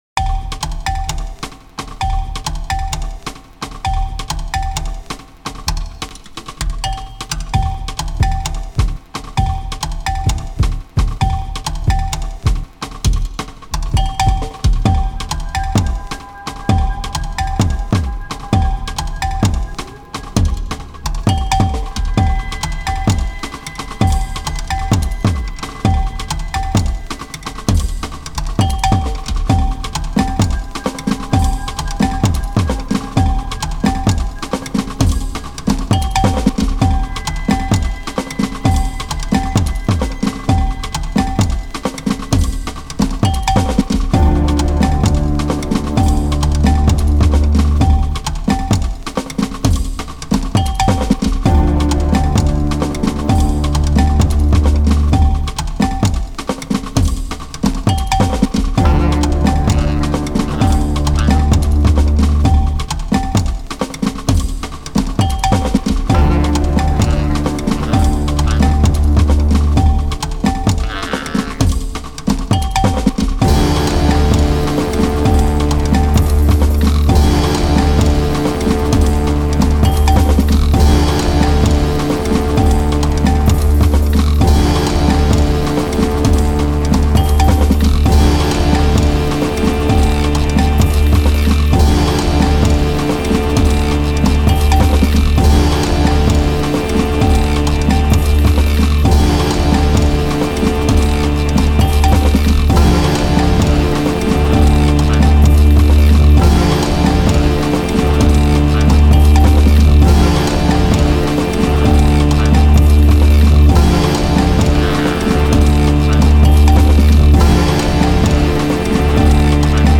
his tracks have a colder resonance.